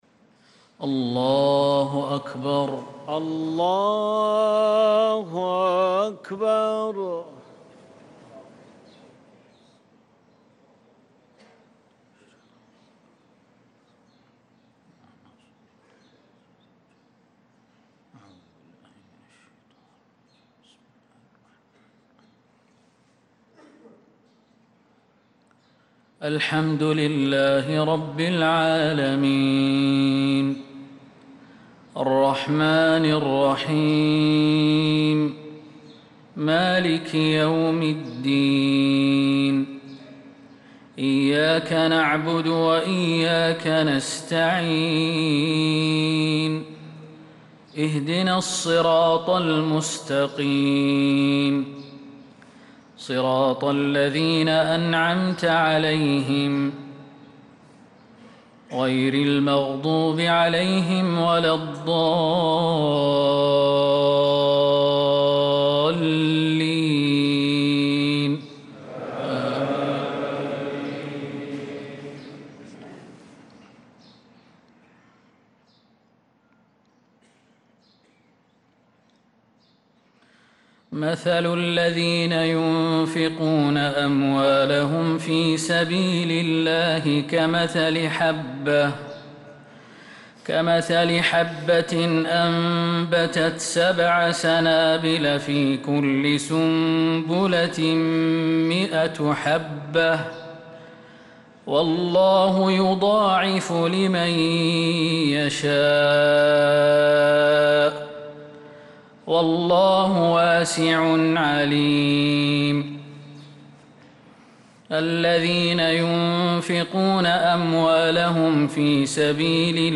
صلاة الفجر للقارئ خالد المهنا 3 ذو الحجة 1445 هـ
تِلَاوَات الْحَرَمَيْن .